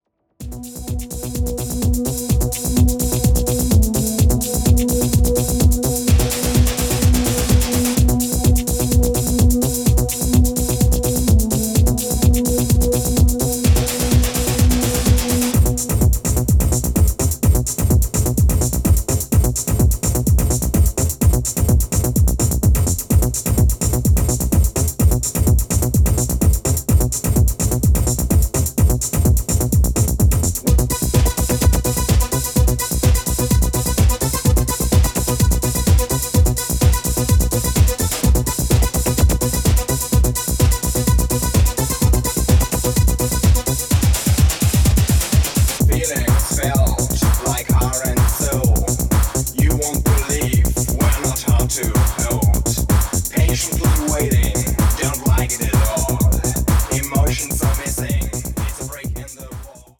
ほぼトランス寸前のパワフルなアルペジオが主導するポストEBM系テクノが今また新鮮に響きます。